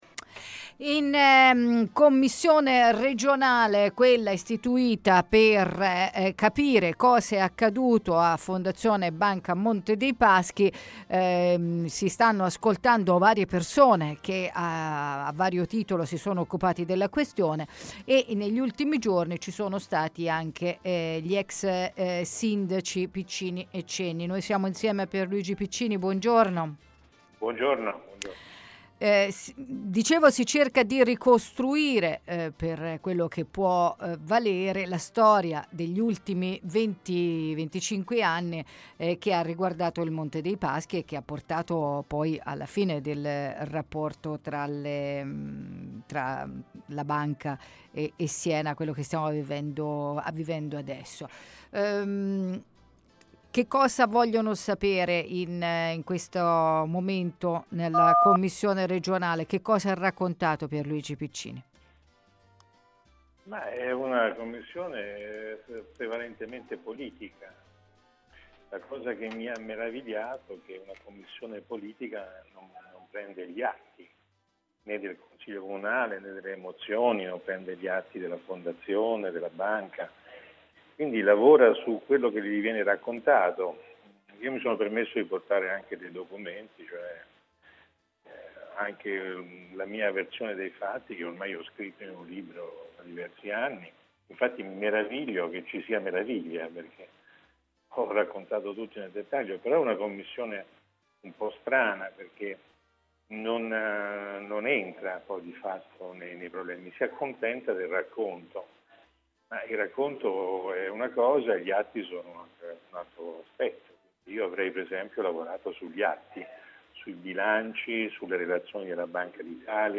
Audizione presso la commissione regionale su banca Mps: Pierluigi Piccini - Antenna Radio Esse